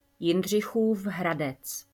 Jindřichův Hradec (Czech pronunciation: [ˈjɪndr̝ɪxuːf ˈɦradɛts]